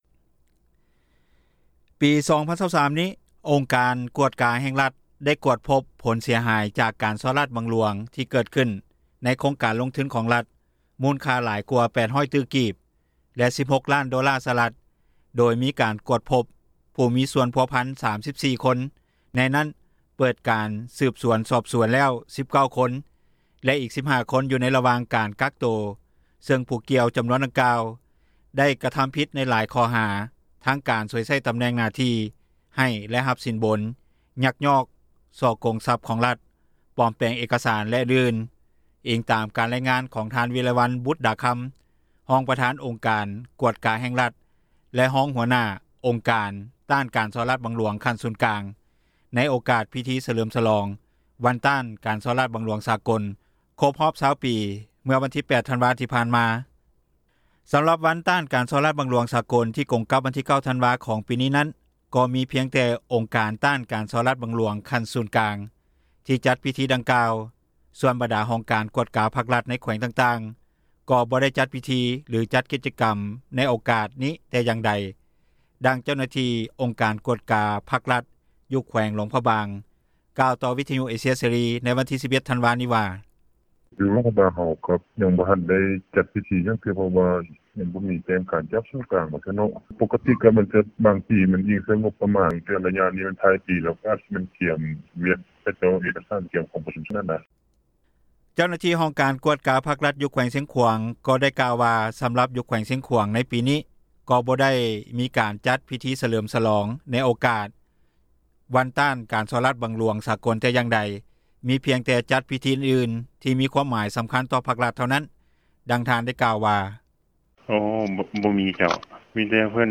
ດັ່ງເຈົ້າໜ້າທີ່ ອົງການກວດກາ ພັກ-ຣັຖ ຢູ່ແຂວງຫຼວງພຣະບາງ ກ່າວຕໍ່ວິທຍຸເອເຊັຽເສຣີ ໃນວັນທີ 11 ທັນວານີ້ວ່າ: